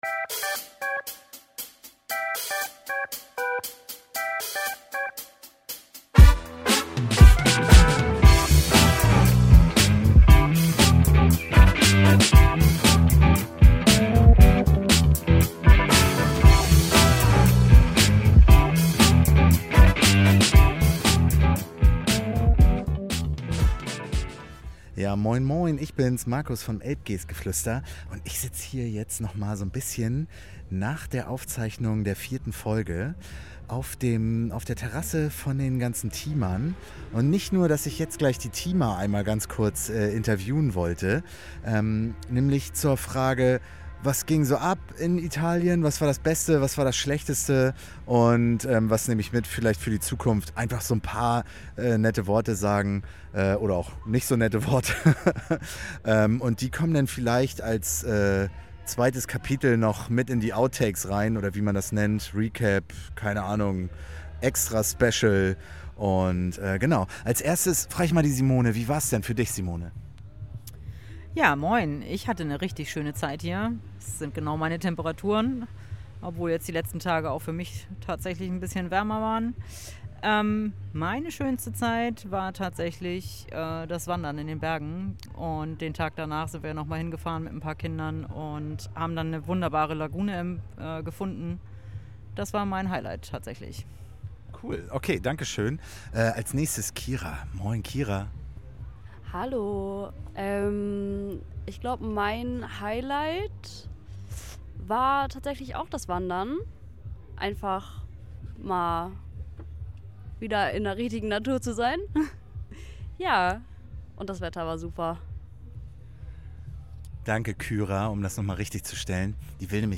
In diesem kleinen Beitrag haben fast alle Teilnehmer ihre Highlights in ein paar kurzen Sätzen zusammengefasst!